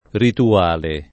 rituale [ ritu- # le ] agg. e s. m.